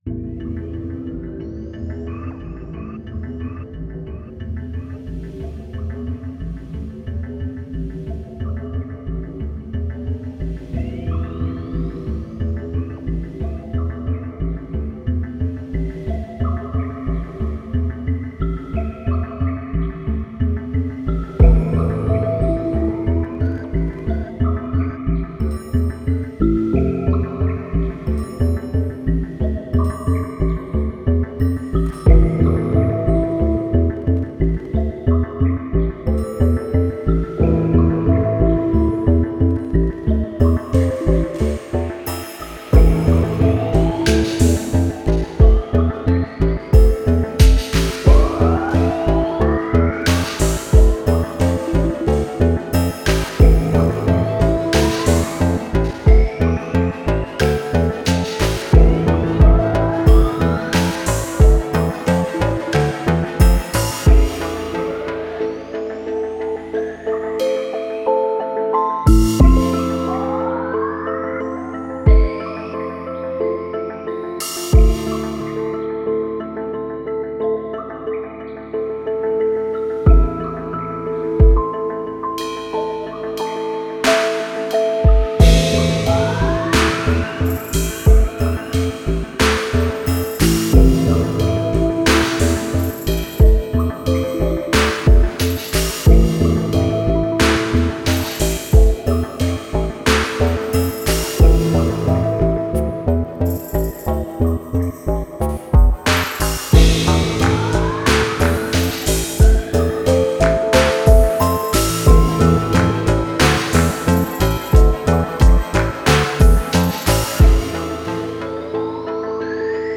Slowly creeping beat with an uneasy dark atmosphere.